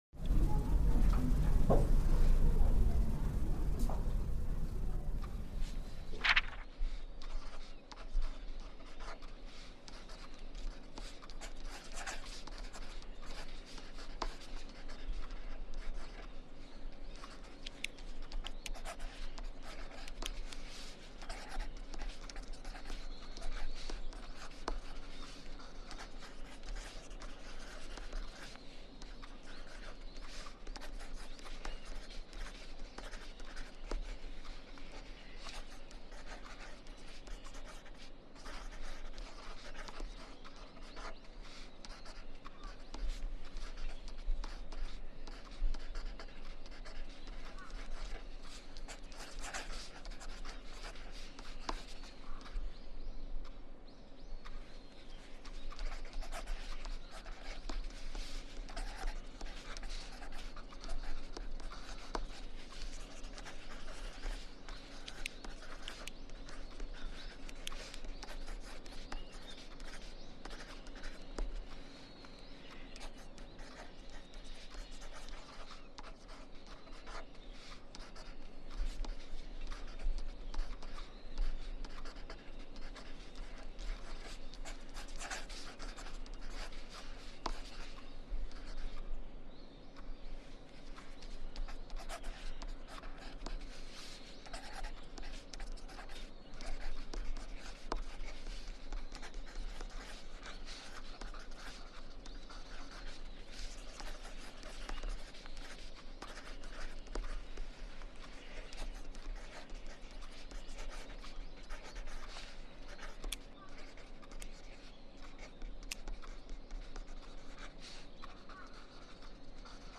Étude silencieuse naturelle · méthode éprouvée 2 heures 25/5